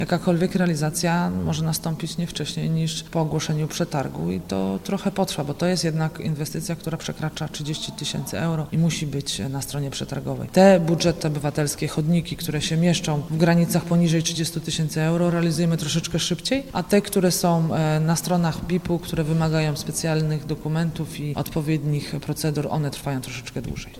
Teraz dopytują, dlaczego jeszcze się nie zaczął, skoro zadanie zostało wybrane w ramach budżetu obywatelskiego. Odpowiada wiceprezydent Agnieszka Surmacz: